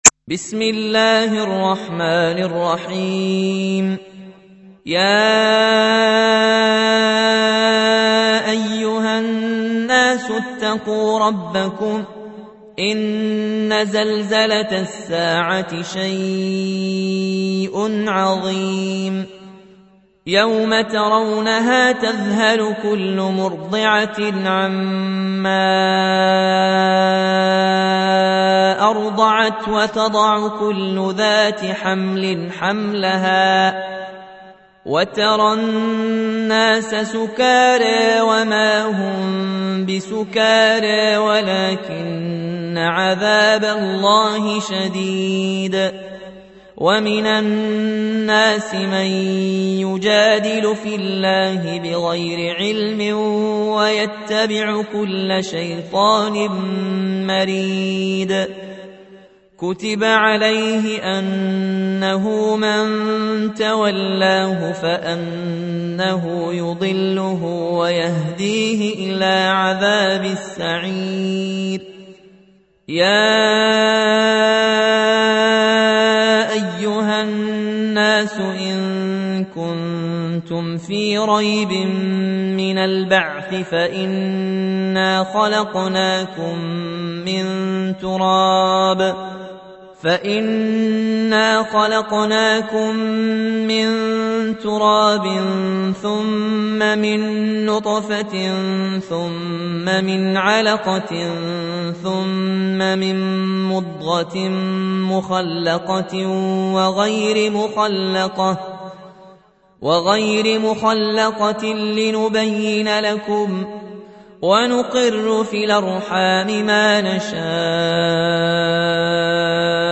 تحميل : 22. سورة الحج / القارئ ياسين الجزائري / القرآن الكريم / موقع يا حسين